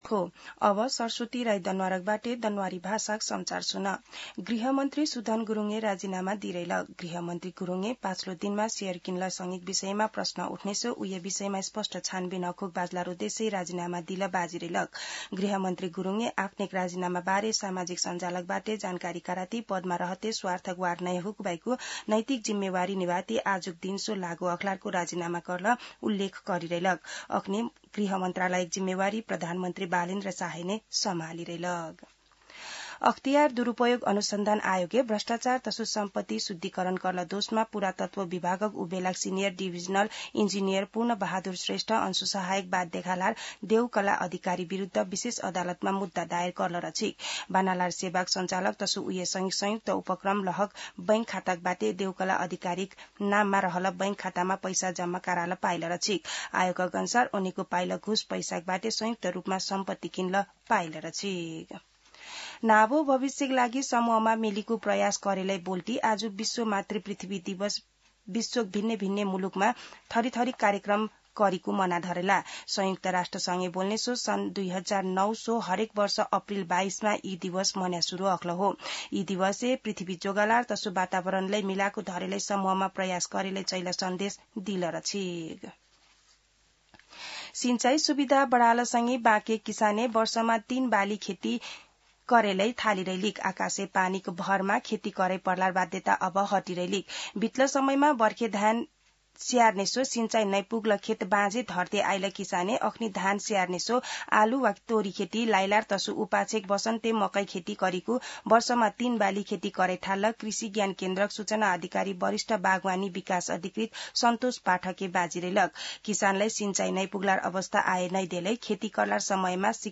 दनुवार भाषामा समाचार : ९ वैशाख , २०८३
Danuwar-News-09.mp3